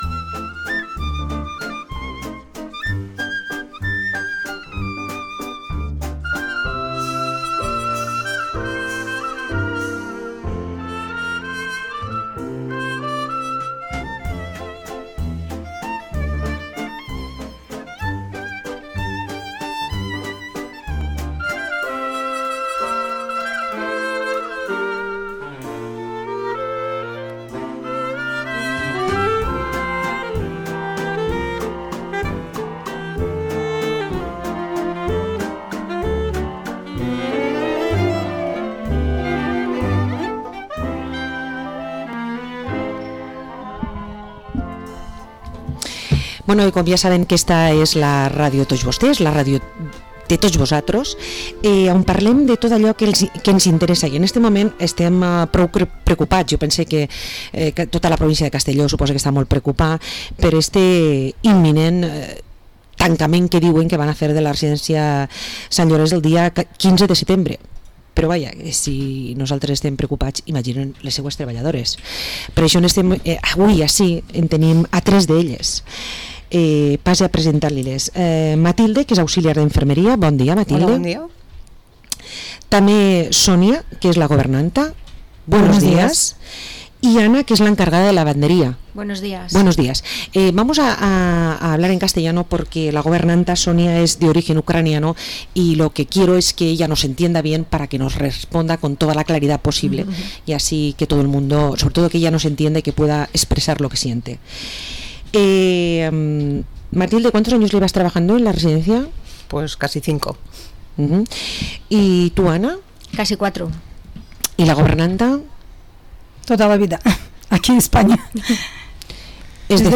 Entrevista a las trabajadoras de la residencia de Sant Llorenç de Vila-real